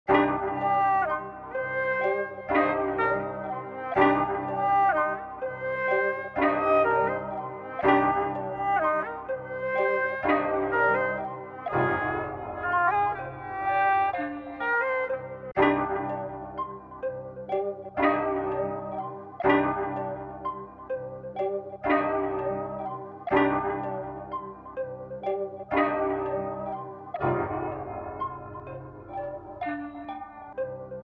lord_no_mercy_76bpm_oz.mp3